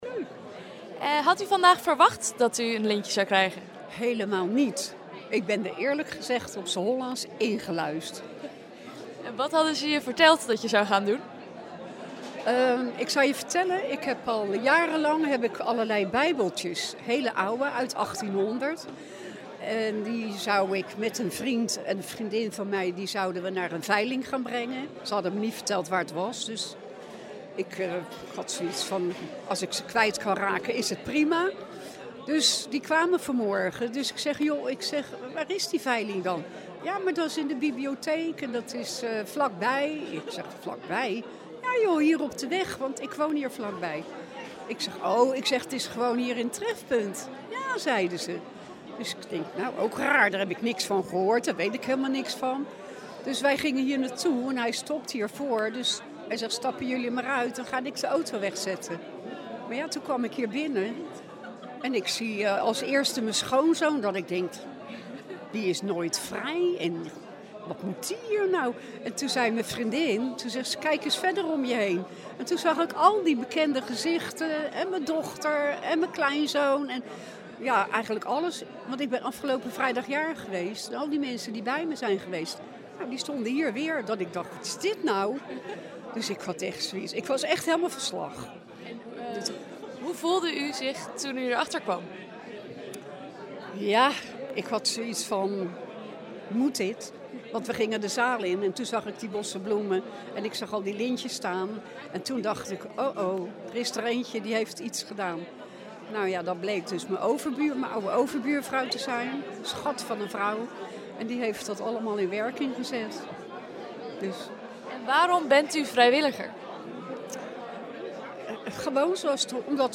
Het interview